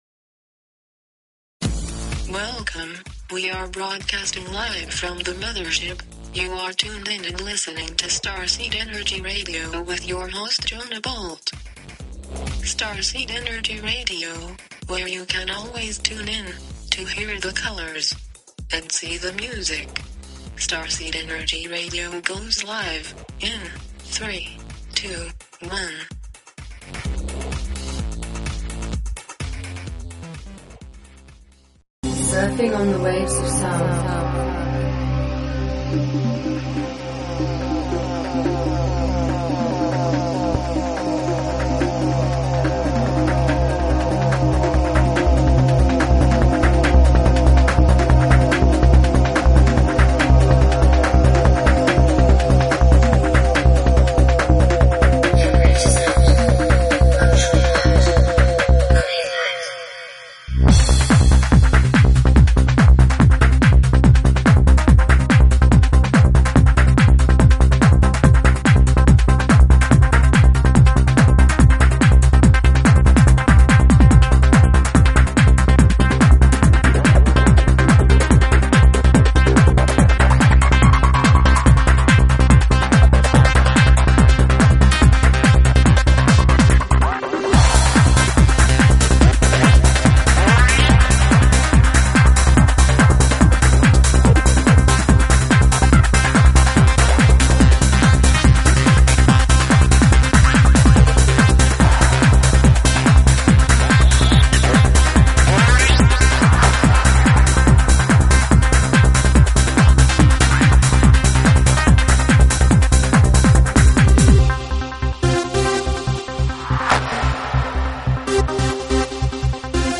Talk Show Episode, Audio Podcast, Starseed_Energy_Radio and Courtesy of BBS Radio on , show guests , about , categorized as